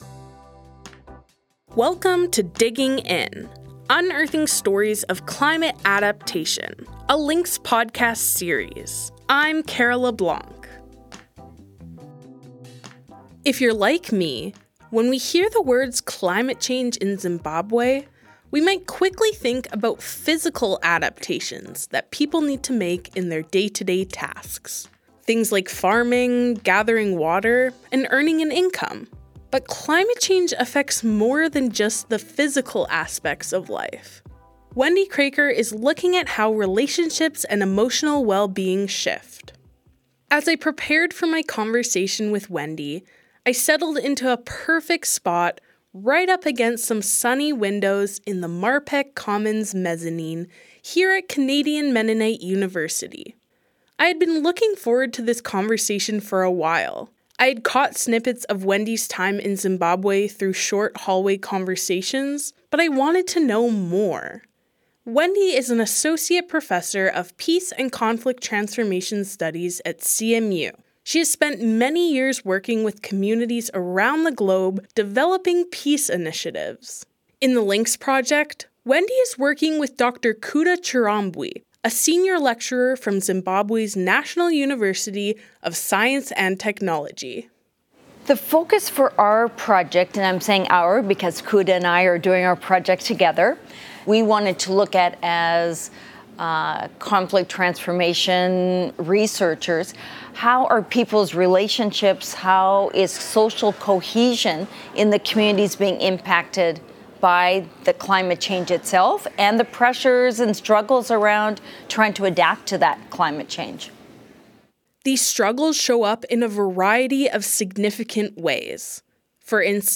Two couples in the Binga district talk about how their relationships have transformed through gender equity training.